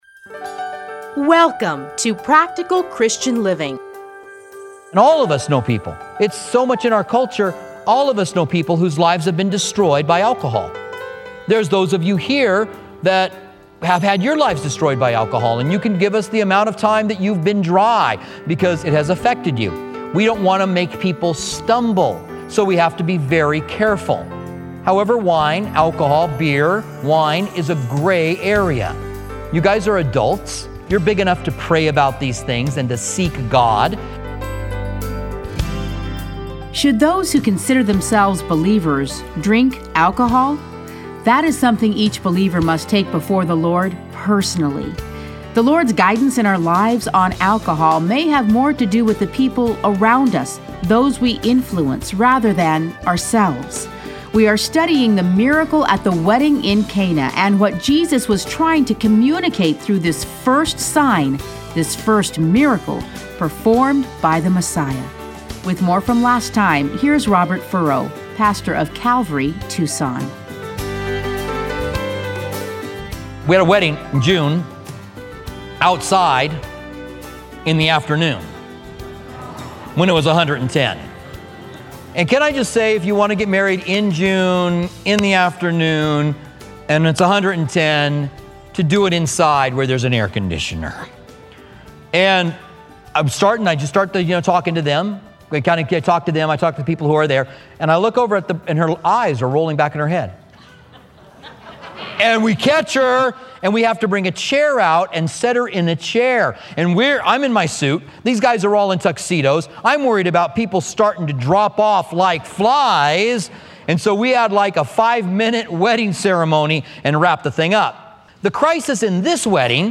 Listen to a teaching from John 2:1-12.